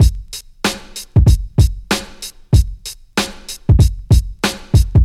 • 95 Bpm 2000s Hip-Hop Drum Loop B Key.wav
Free breakbeat - kick tuned to the B note. Loudest frequency: 1061Hz